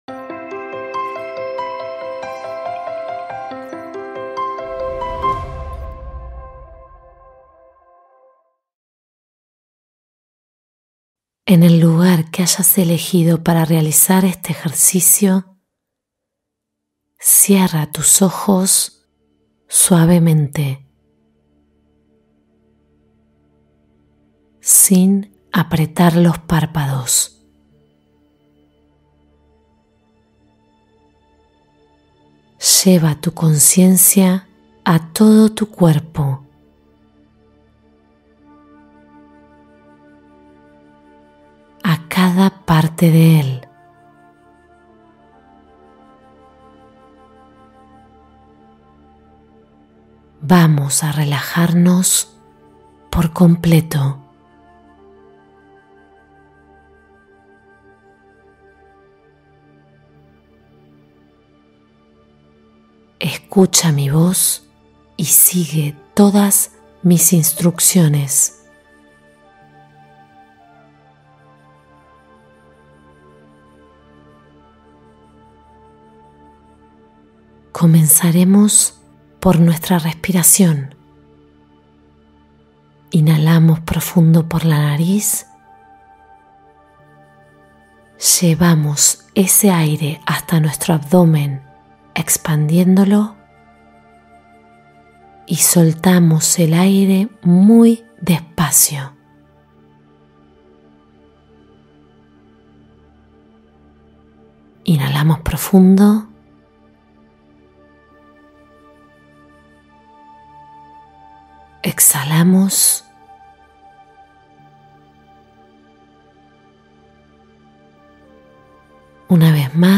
Soltar la Culpa del Cuerpo y la Mente: Meditación de Liberación Emocional